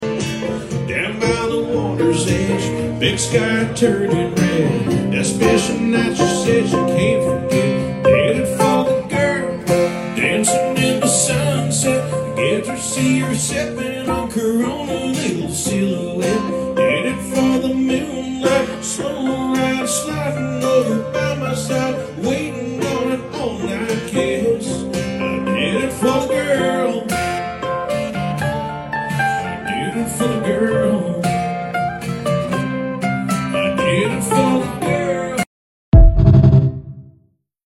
Crowd Sound Effects Free Download